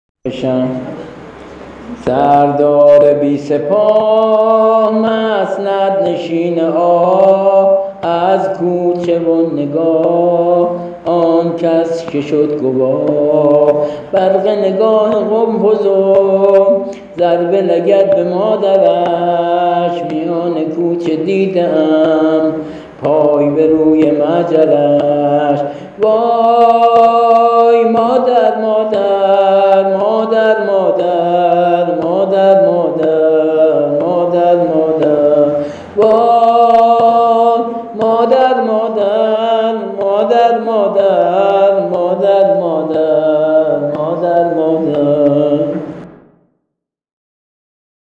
◾نوحه‌شهادت امام حسن(ع)
◾با ملودی و سبک‌جدید